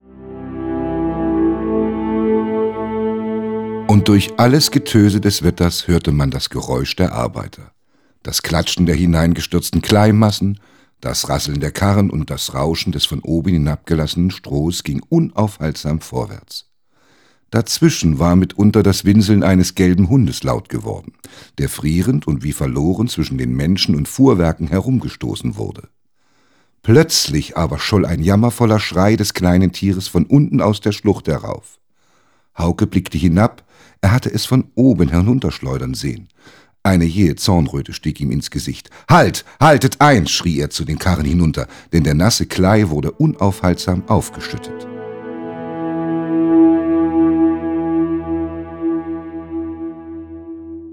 – Theodor Storm: Der Schimmelreiter (Gesprochen:)